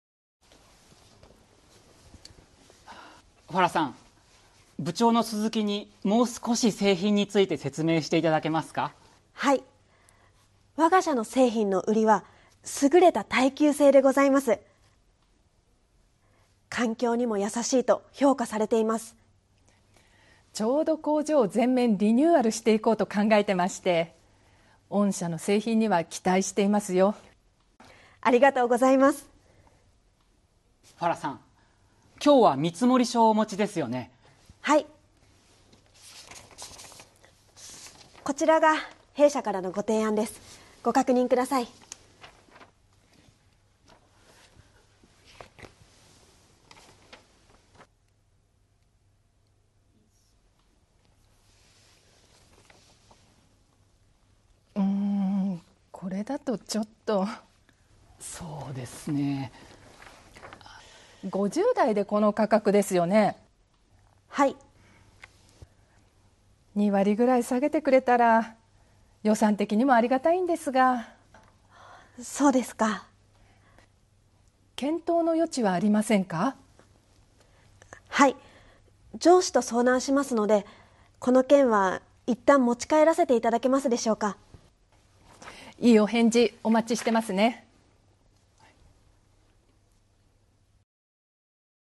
Role-play Setup
skit37.mp3